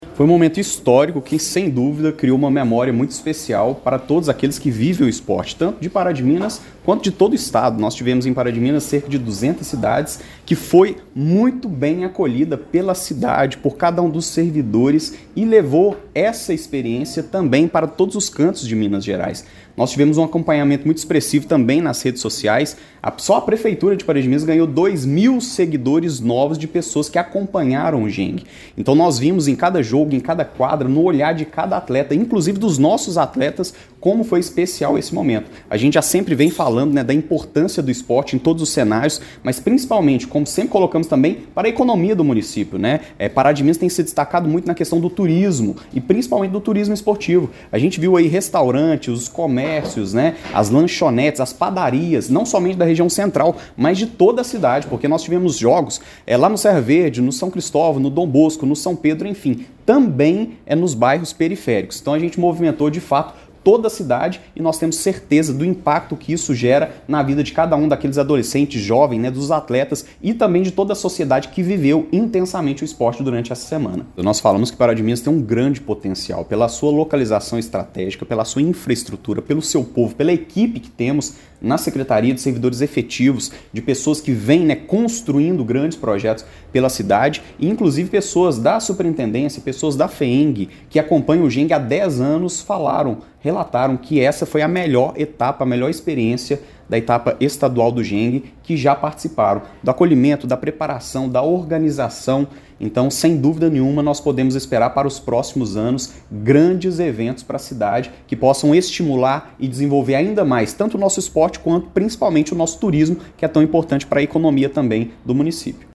O vice-prefeito Luiz Lima destacou que a cidade foi elogiada não só pela hospitalidade, mas também pela organização e estrutura, sendo apontada por profissionais experientes como a melhor etapa estadual já realizada.